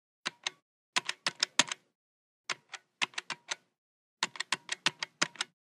Звуки кнопок
Звук кнопочного телефона:
zvuk-knopochnogo-telefona.mp3